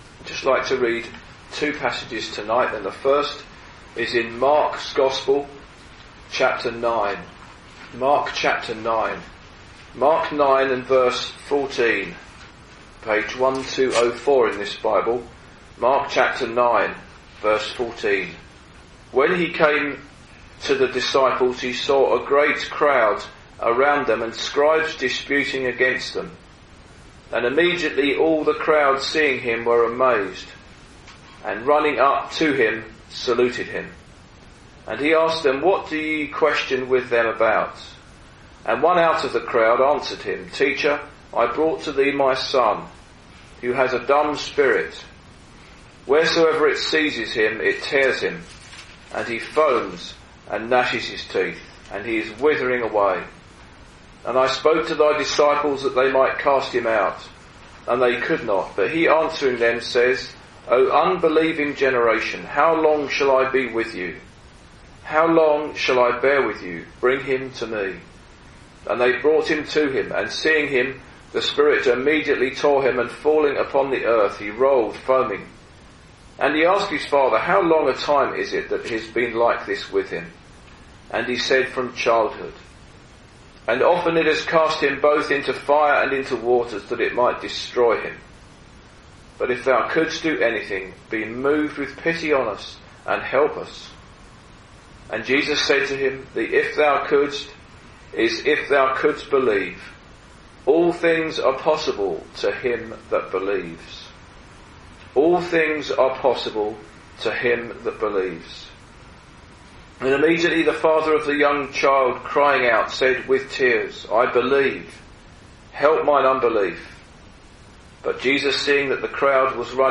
In this Gospel preaching, you will hear about the greatness of our need and the greatness and power of the Lord Jesus Christ.